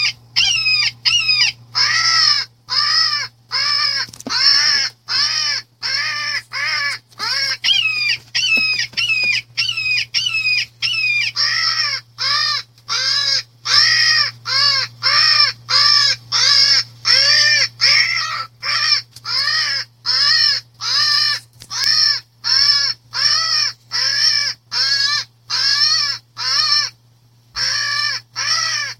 Crow hawk death cry 2 ringtone free download
Animals sounds